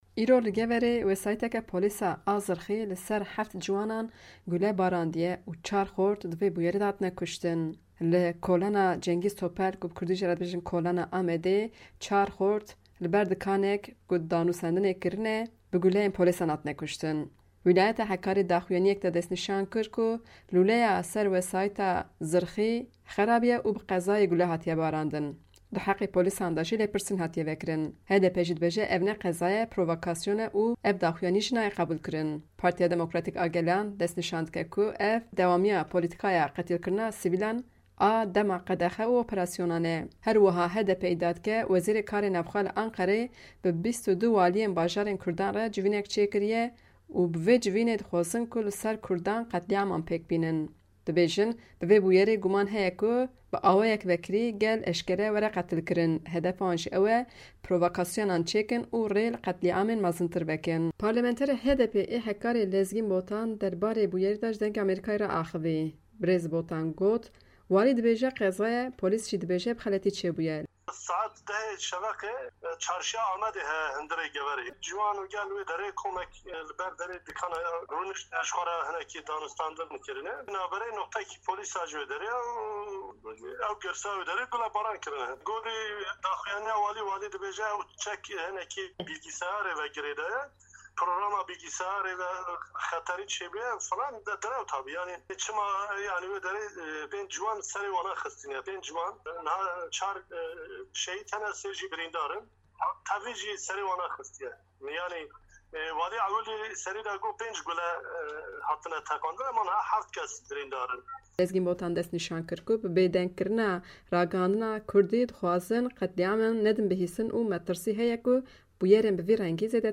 Parlamenterê Colemêrgê yê Hekkarê Lezgîn Botan ji Dengê Amerîka re behsa vê bûyerê kir.